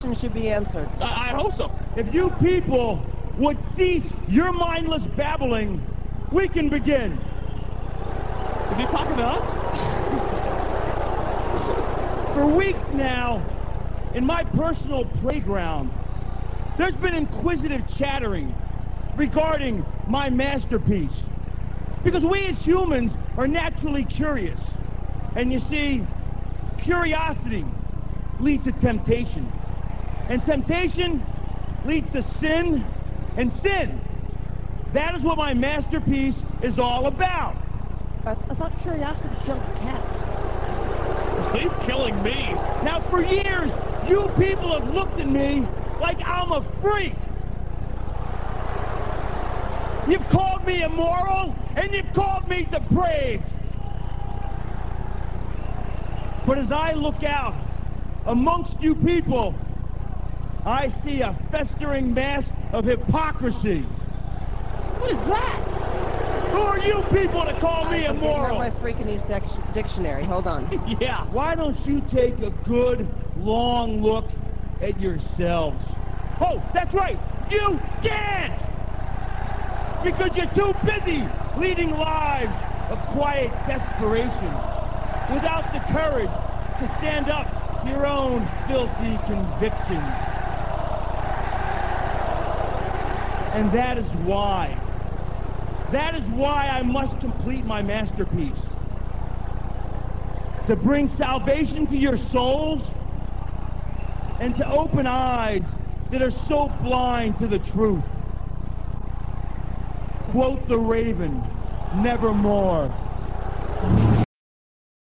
raven83.rm - This clip comes from WWE HEAT - [11.10.02]. Raven lays out the reasons for his upcoming masterpiece and the changes it will effect amongst the fans' perceptions of him.